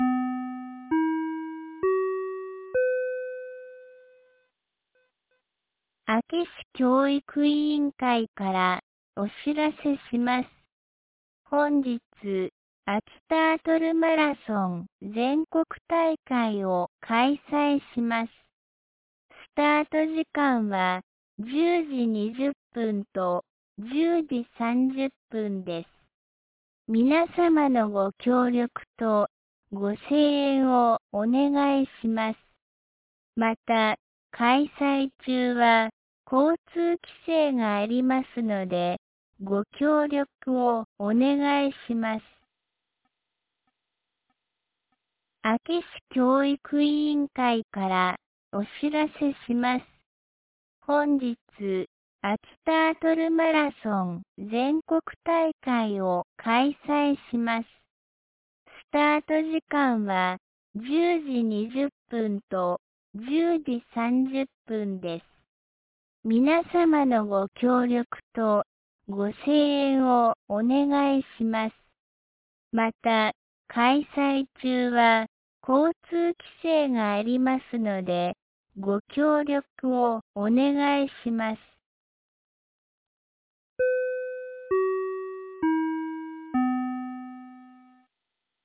2025年12月14日 08時31分に、安芸市より全地区へ放送がありました。